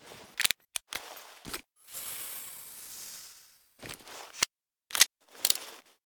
injector.ogg